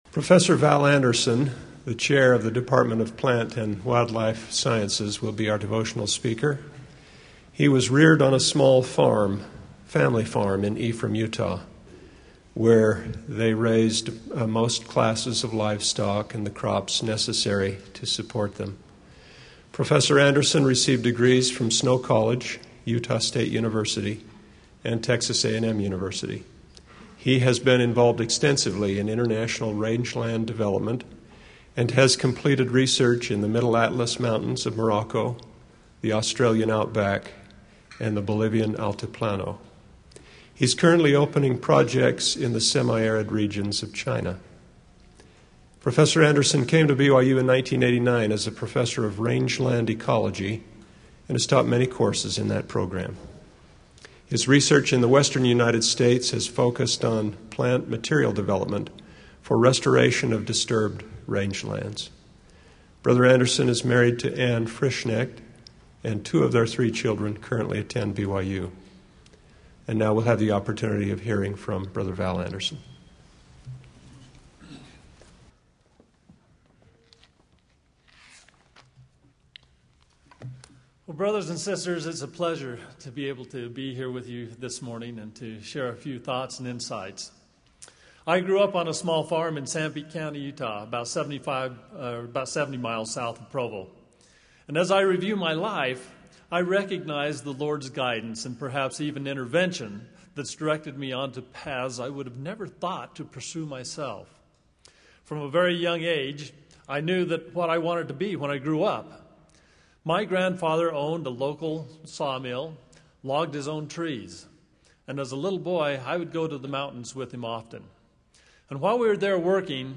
Click to copy link Speech link copied Devotional “Into the Burn!”